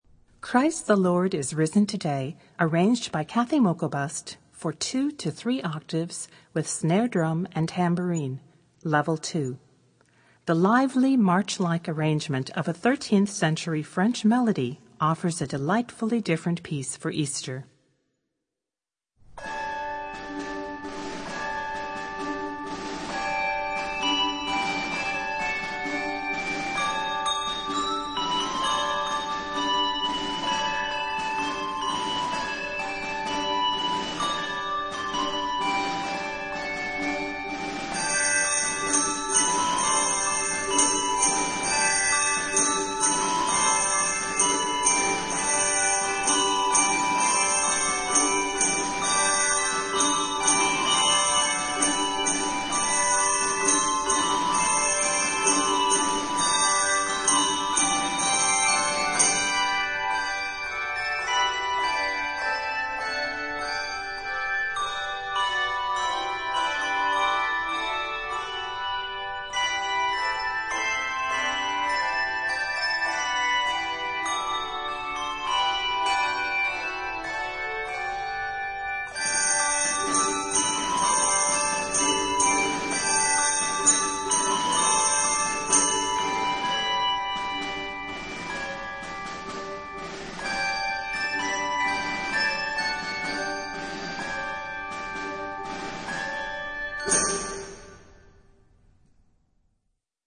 Handbells 3-5 Octave